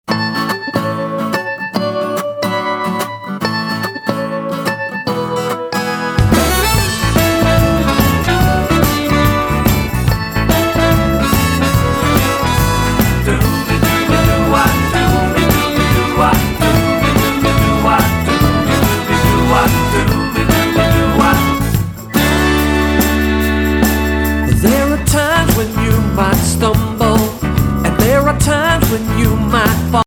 The songs are joyful, optimistic, and lots of fun.